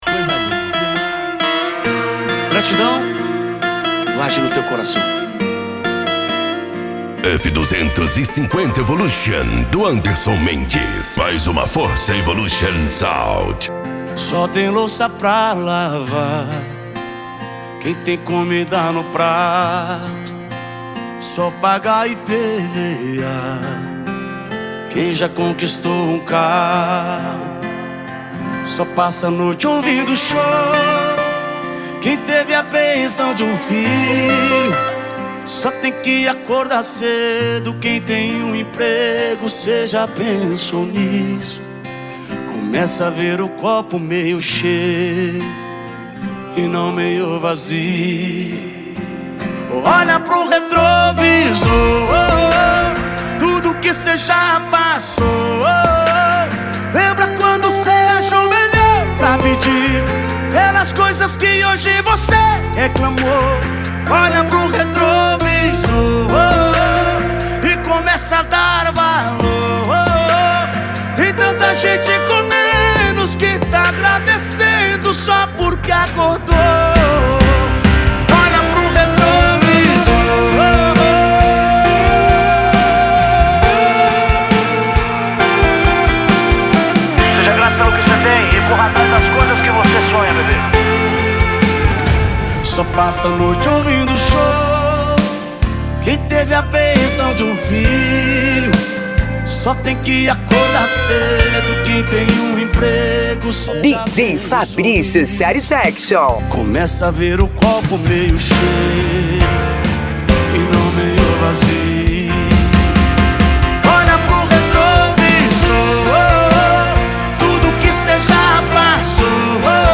Modao
SERTANEJO